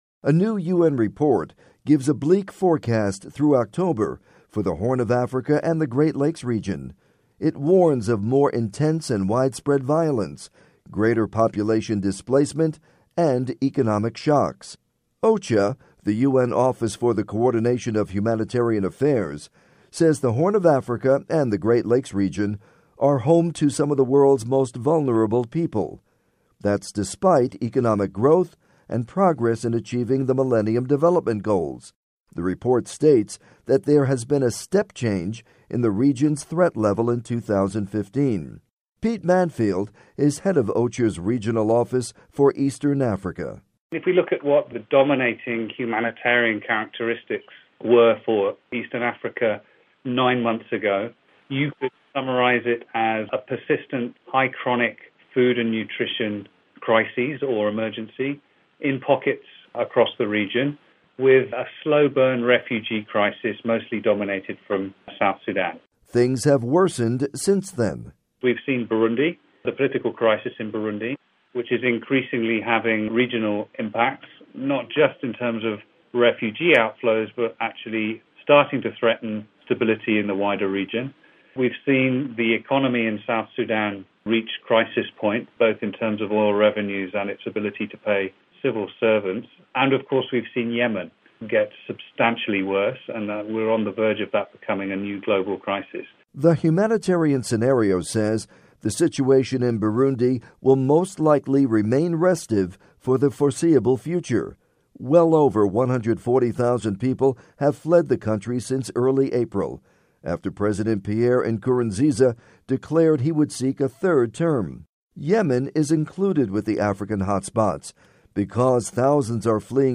report on OCHA outllok for Horn and East Africa